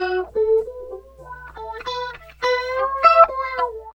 71 GTR 2  -L.wav